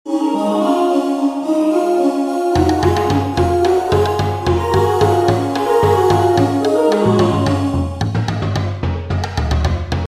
Here is a variation, where also the instruments have been replaced: